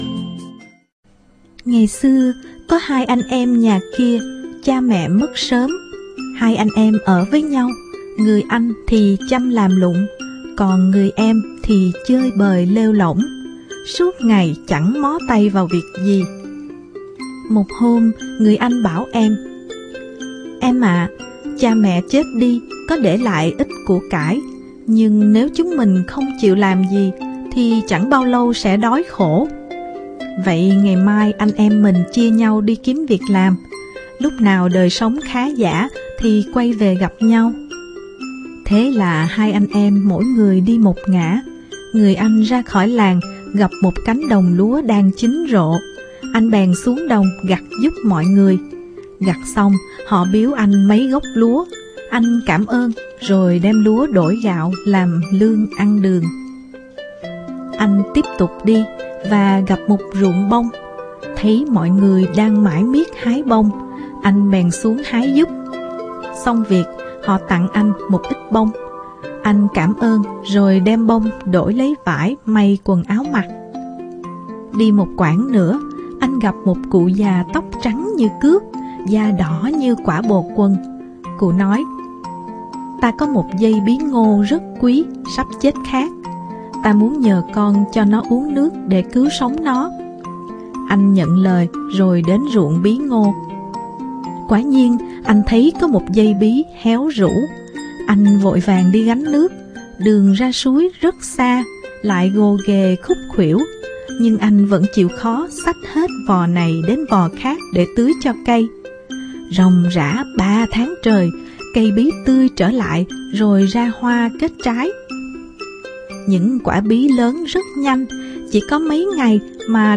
Sách nói | Truyện cổ tích Hai anh em